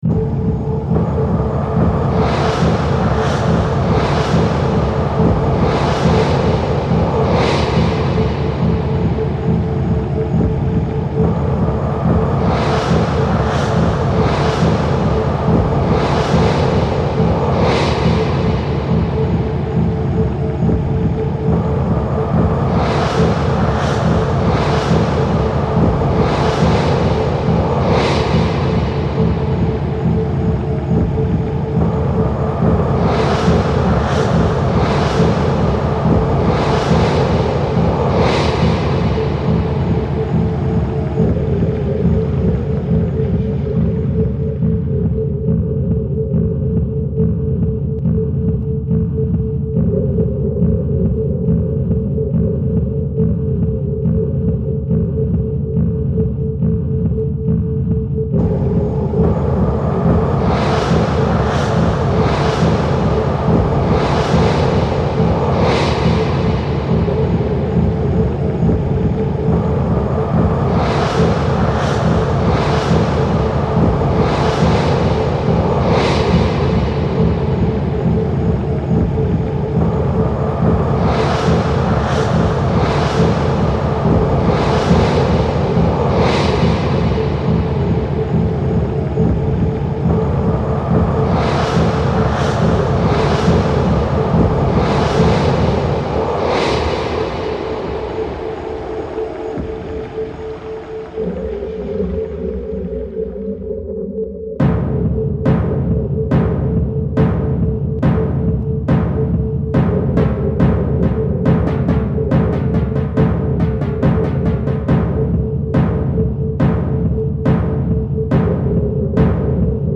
インダストリアル色は薄く、どちらかというとアンビエント、ダーク・アンビエント、エクスペリメンタル的色合いが強いのが特徴。